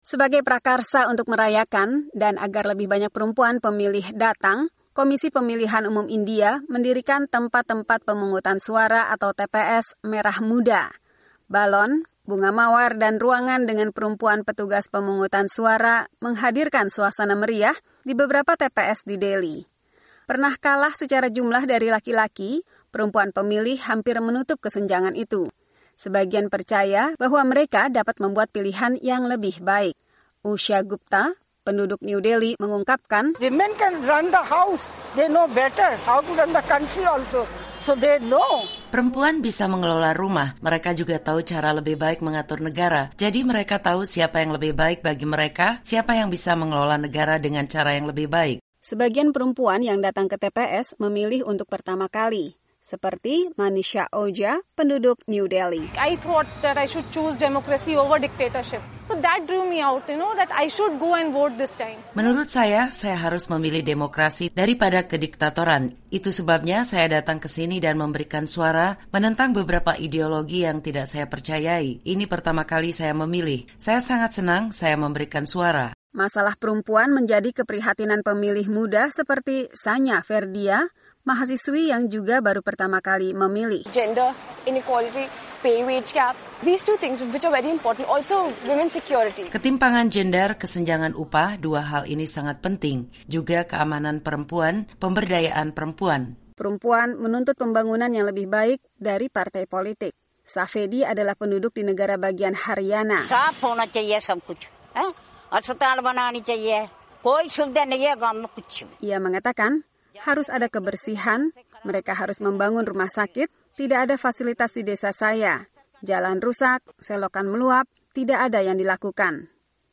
Di India, sementara jumlah perempuan pemilih melonjak dalam pemilu yang sedang berlangsung, kesenjangan dengan laki-laki diperkirakan akan tertutup, membuat partai-partai politik lebih memperhatikan masalah perempuan. Laporan